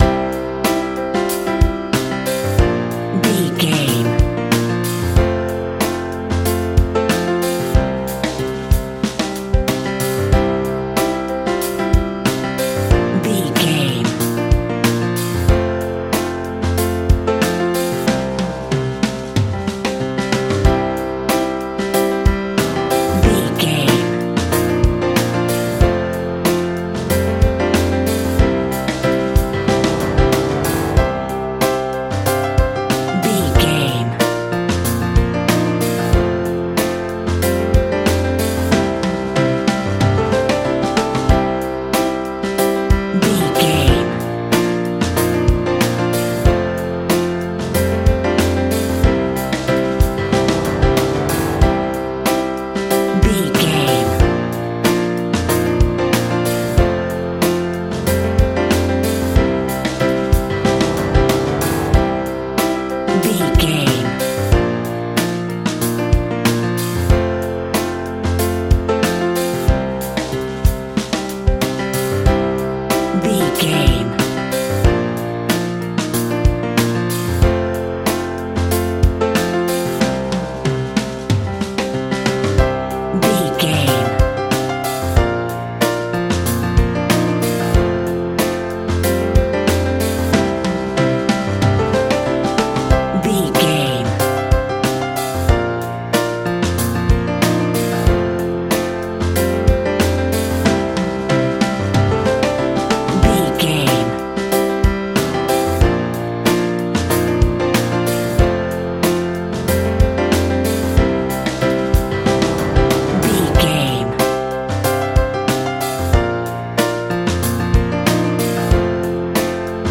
Ionian/Major
groovy
powerful
electric guitar
bass guitar
drums
organ